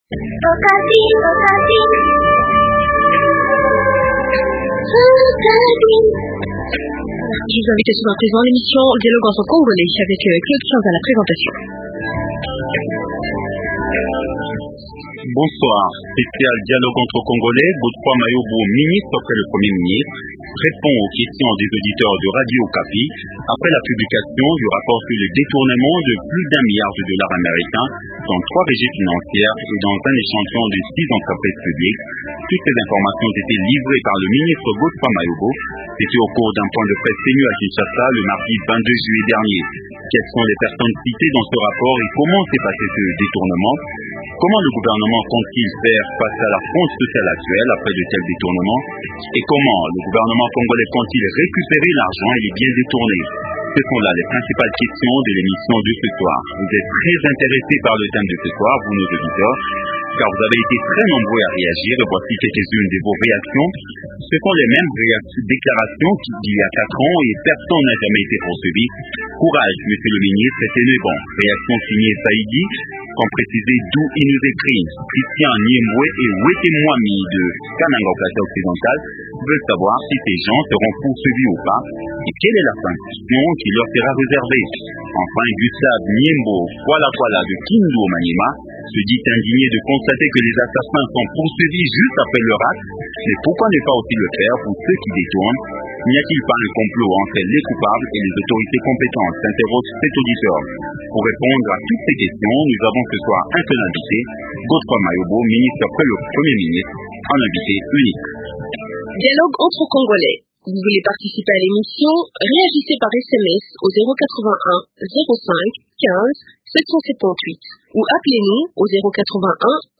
Godefroid Mayobo repond.